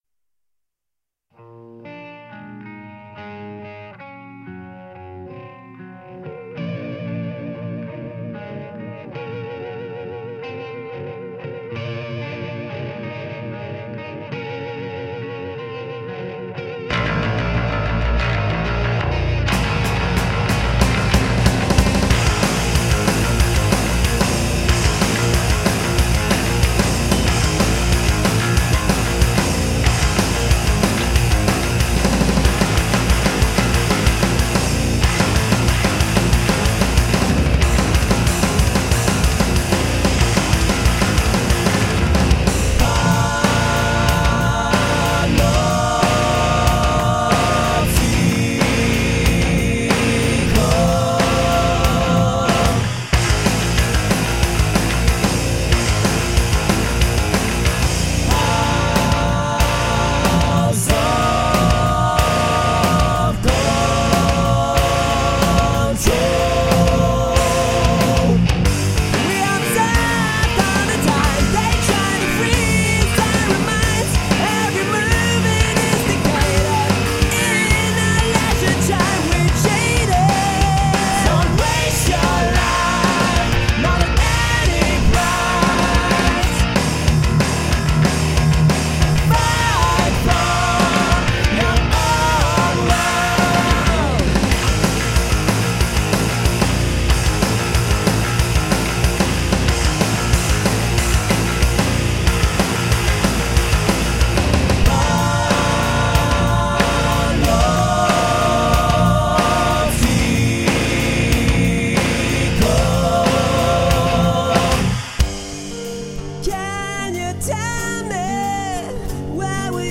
Alternative Rock, Nu Rock, Progressive Rock